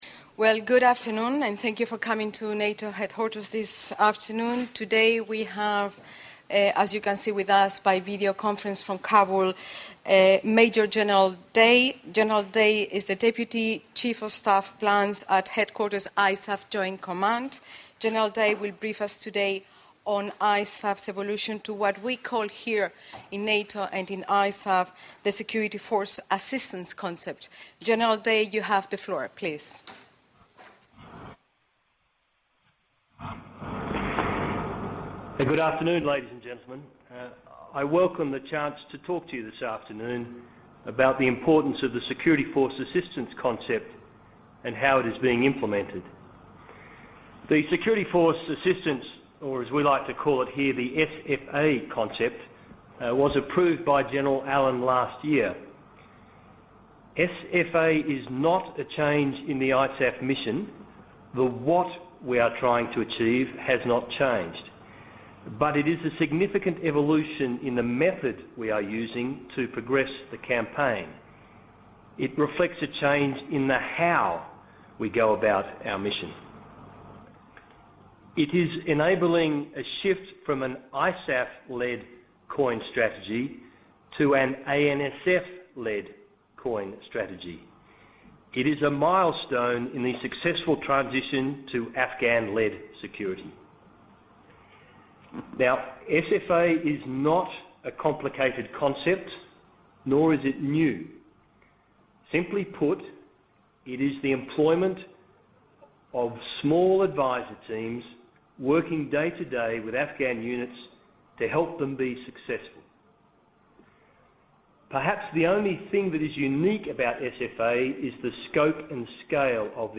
Press conference by Major General Stephen Day, Deputy Chief of Staff, Plans, ISAF Joint Command (IJC)